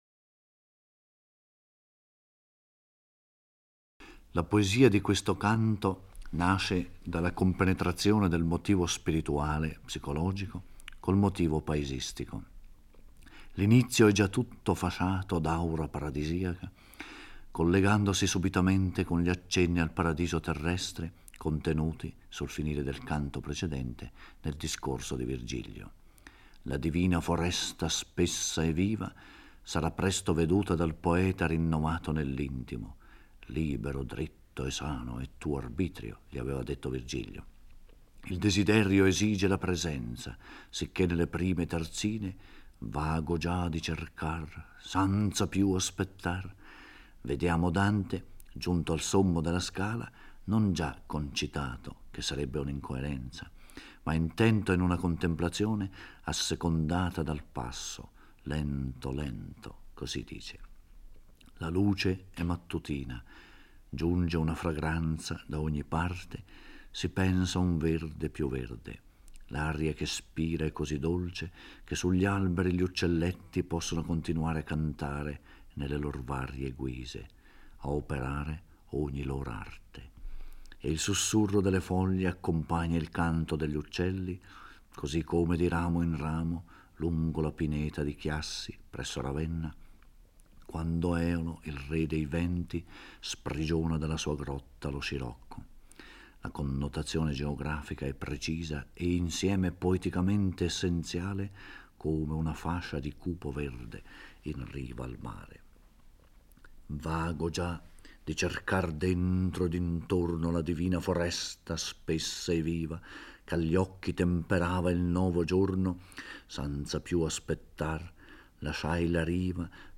Giorgio Orelli legge e commenta il XXVIII canto del Purgatorio. Dante si allontana da Virgilio e da Stazio, avviandosi lentamente attraverso il prato fiorito verso la foresta del Paradiso terrestre.